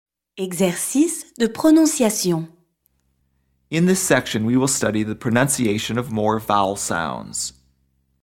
PRONONCIATION
i – This letter sounds like the “ee” in “tee.”
ou – These letters together sound like “oo” in “school.”